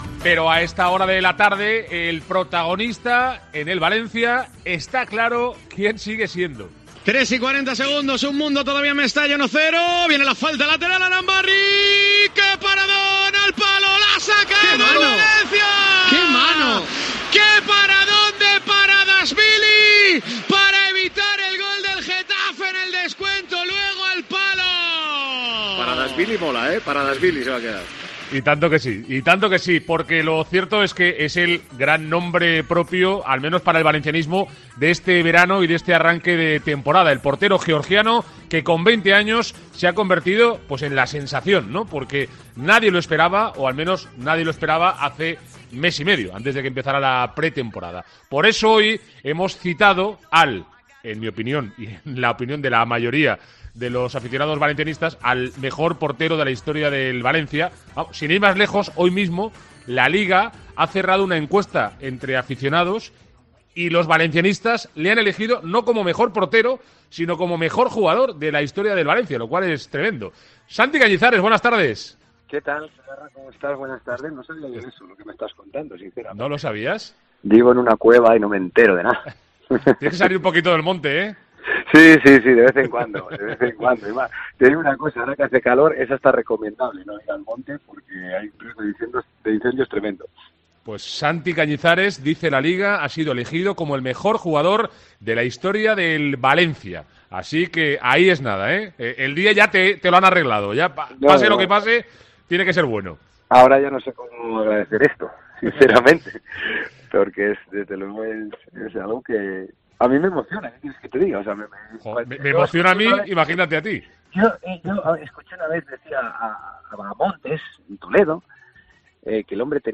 El día en el que se ha cerrado una encuesta a través de la plataforma de LaLiga en la que ha sido elegido como mejor futbolista de la historia del Valencia CF , Santi Cañizares analiza en Deportes COPE Valencia la peculiar situación que vive la portería che, con cuatro inquilinos y con la abrupta irrupción de Giorgi Mamardashvili.